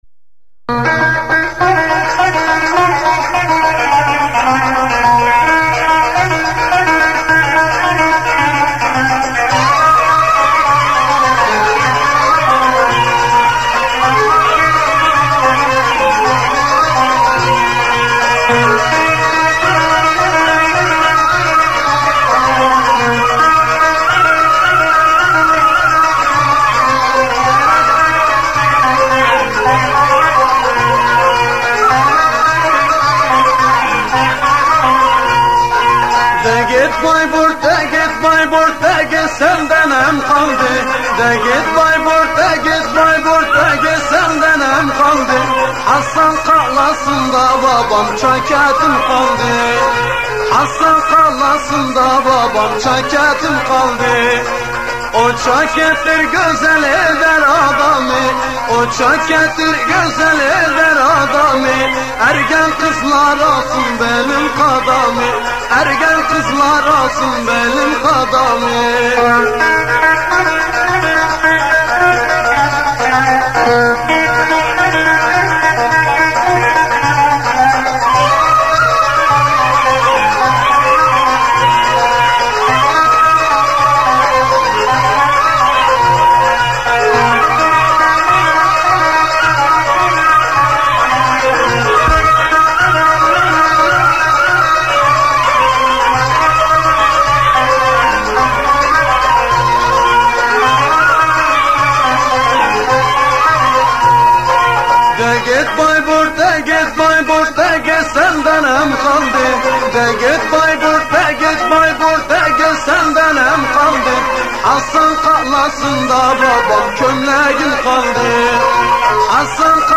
Listen And Download Turkish Music